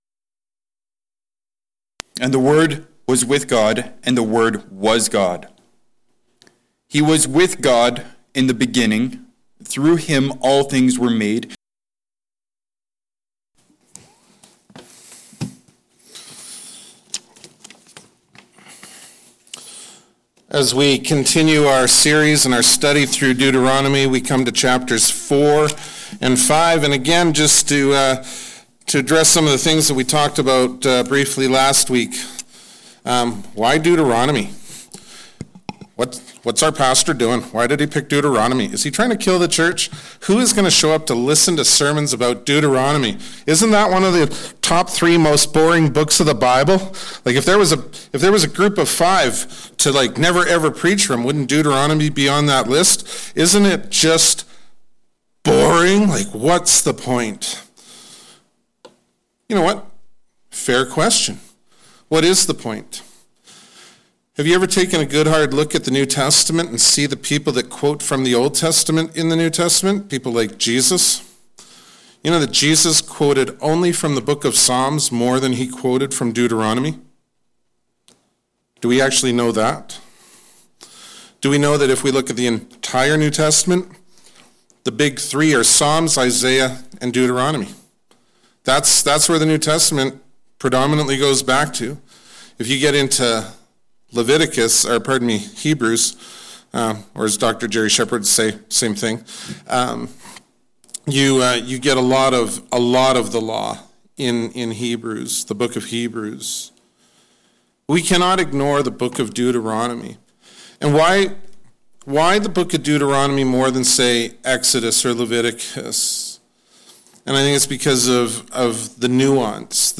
2021 Manifestations of Grace Preacher